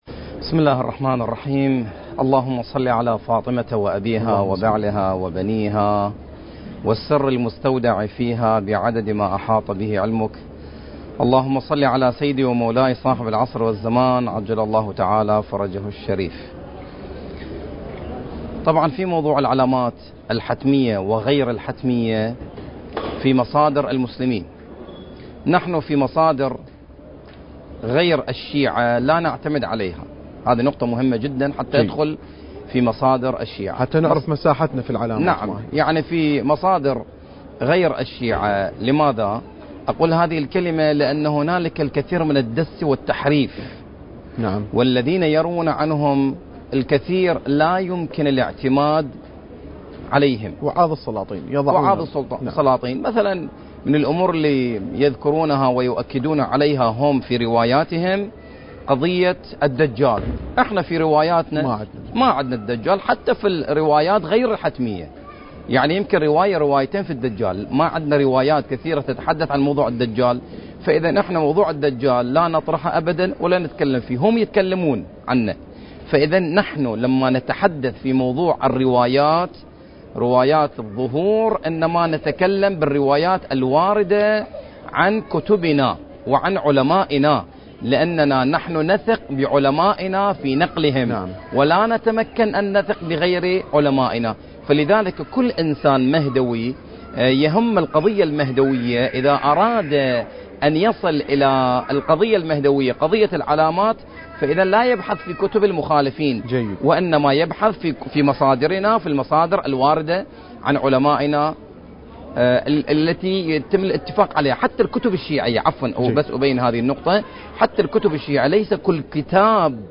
برنامج: يا مهدي الأمم المكان: مسجد السهلة المعظم